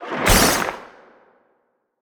Sfx_creature_spinner_attack_01.ogg